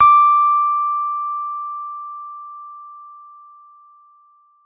Rhodes_MK1